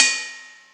• Crash Single Shot D# Key 05.wav
Royality free crash single shot tuned to the D# note. Loudest frequency: 4519Hz
crash-single-shot-d-sharp-key-05-Er9.wav